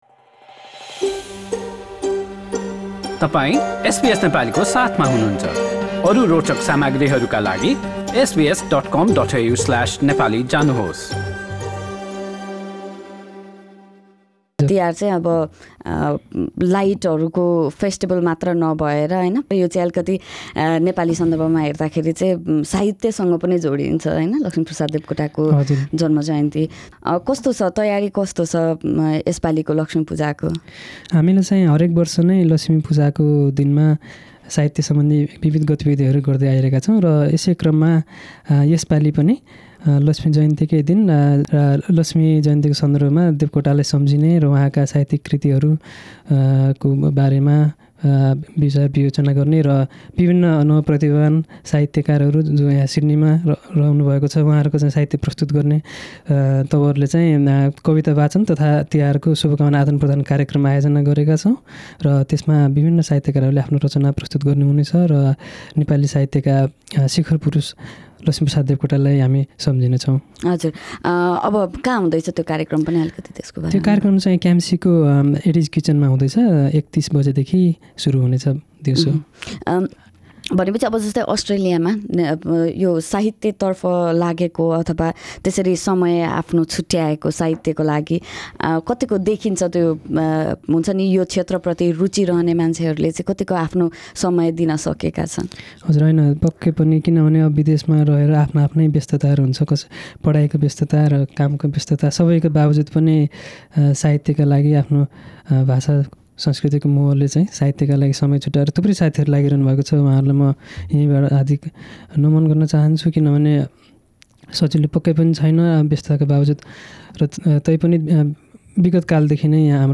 एसबीएस नेपाली सँग गरेको कुराकानी